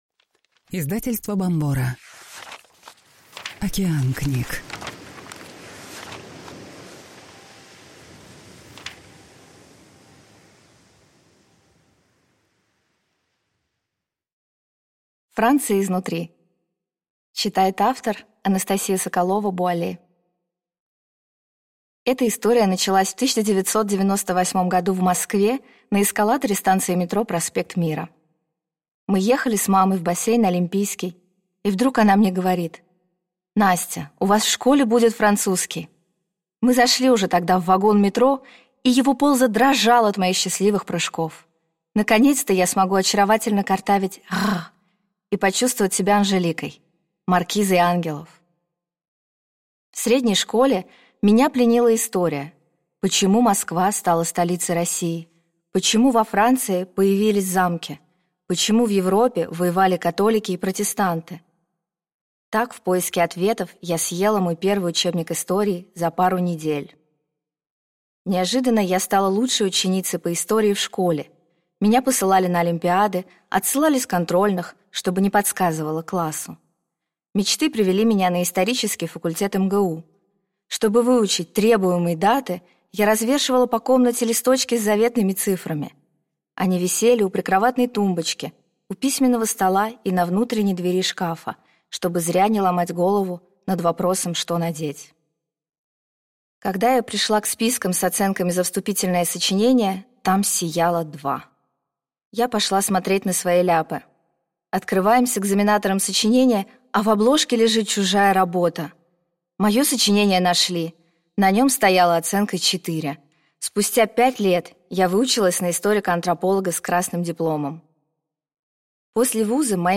Аудиокнига Франция изнутри. Как на самом деле живут в стране изысканной кухни и высокой моды?